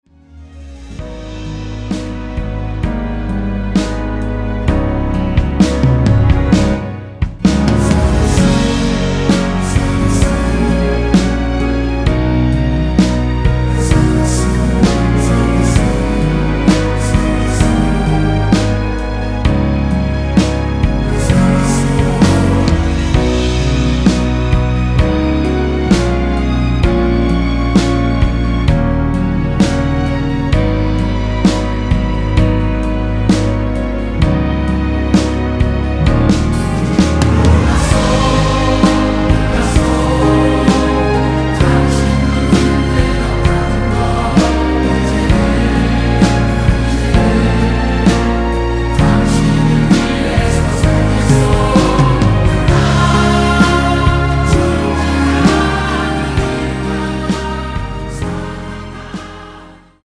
원키에서(-3)내린 코러스 포함된 MR입니다.(미리듣기 확인)
F#
앞부분30초, 뒷부분30초씩 편집해서 올려 드리고 있습니다.